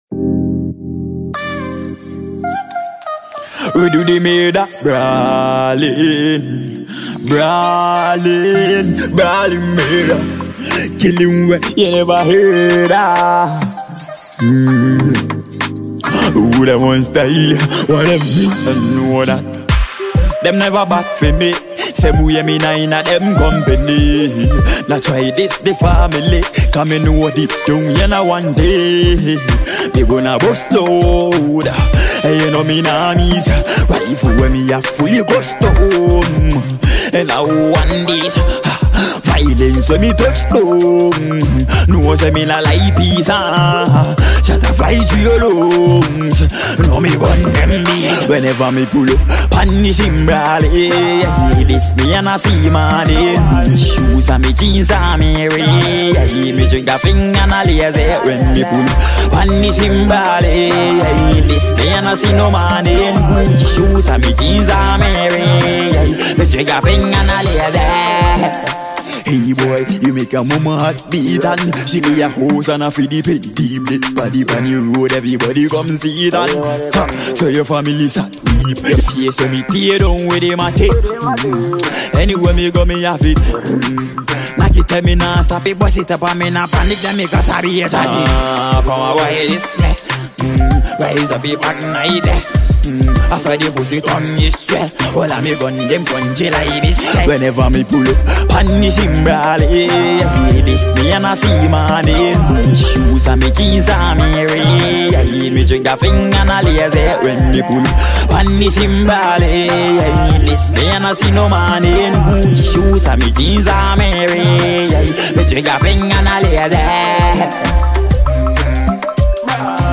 Ghanaian reggae-dancehall musician